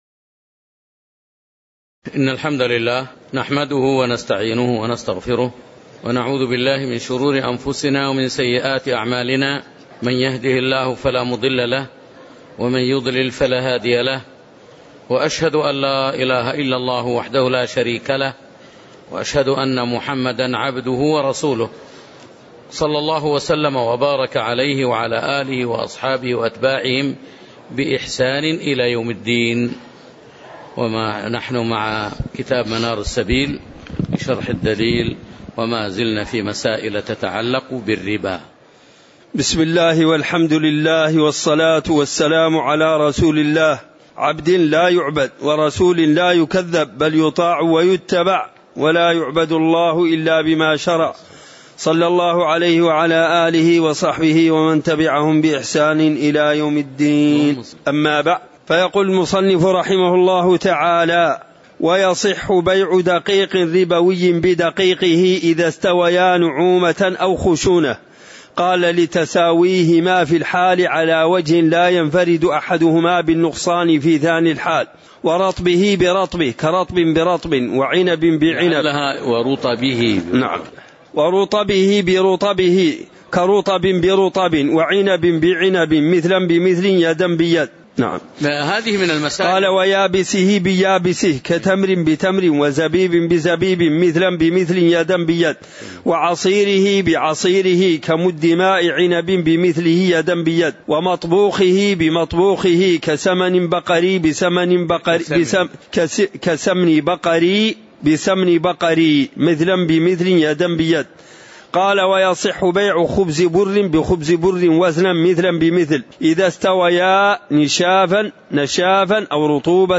تاريخ النشر ٥ صفر ١٤٤٠ هـ المكان: المسجد النبوي الشيخ